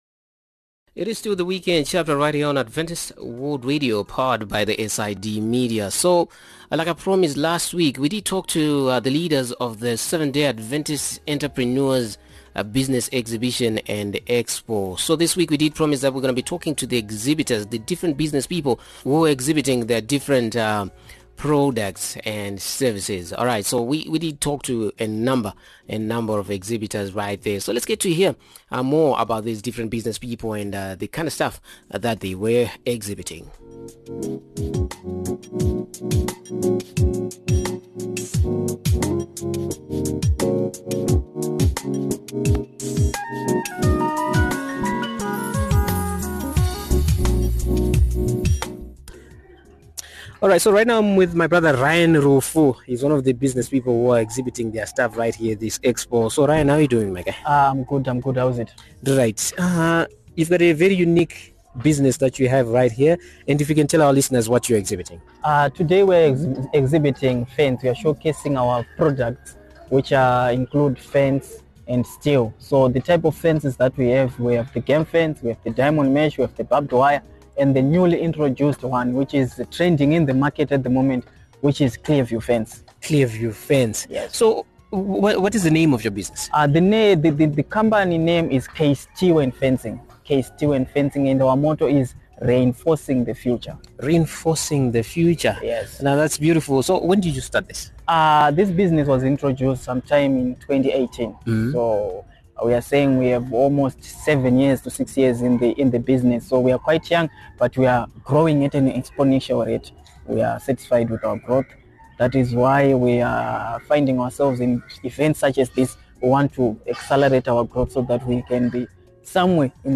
So this evening we are in Harare were I am going to be speaking to different business people who were exhibiting different things at the recently held Adventist business Expo.